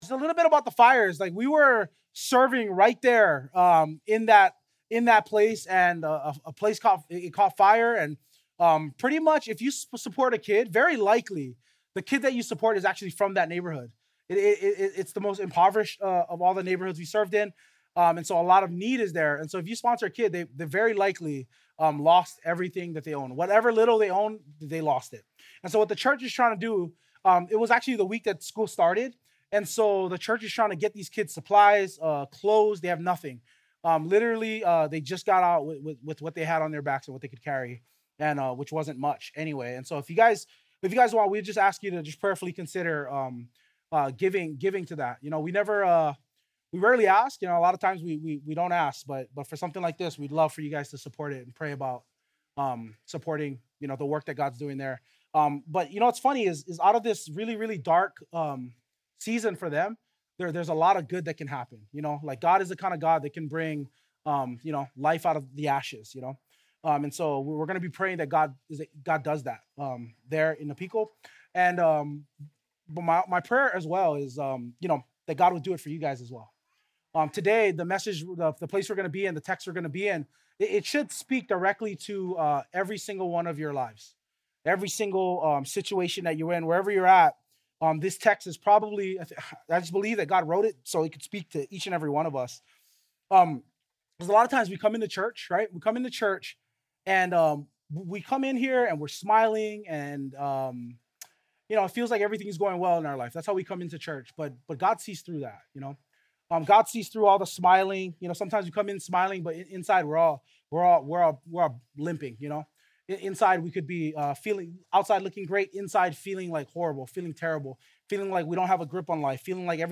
2025 Ultimate Life-Giver Preacher